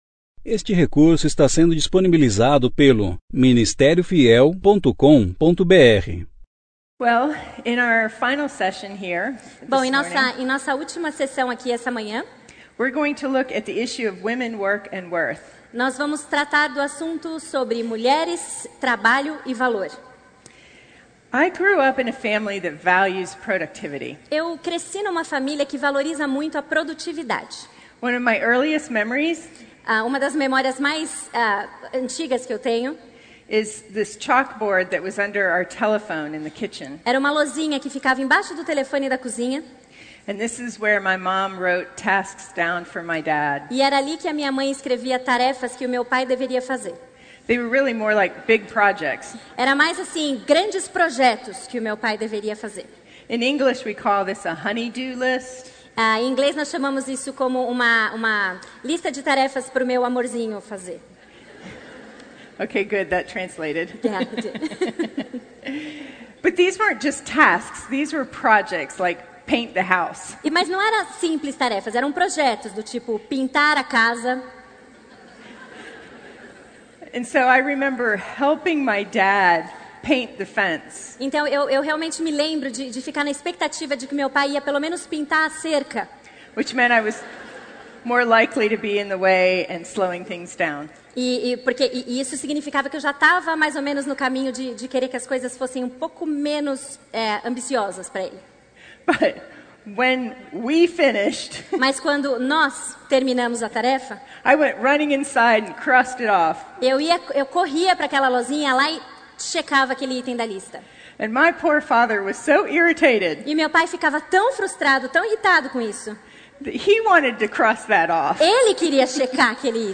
Conferência: 3ª Conferência Fiel para Mulheres – Brasil Tema: Renovadas Ano: 2018 Mensagem: Mulheres